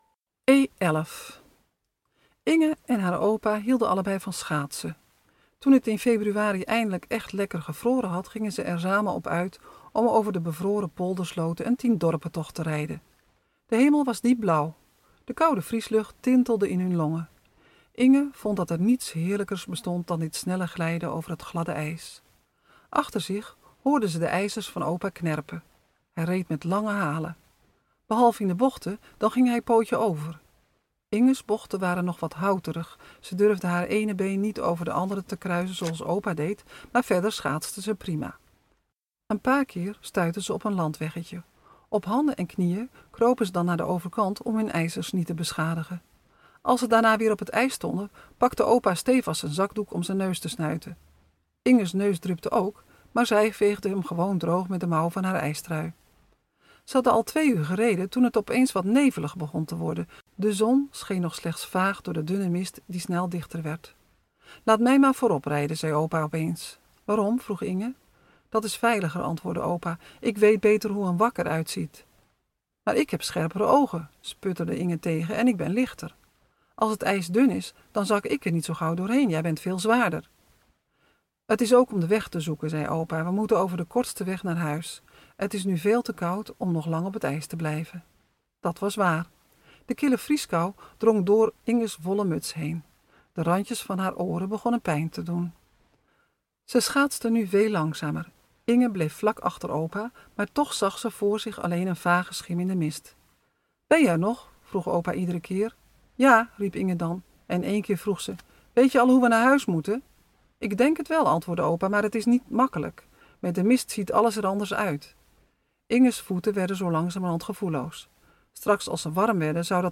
spookverhaal-e-11.mp3